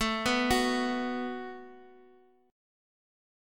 B5/A Chord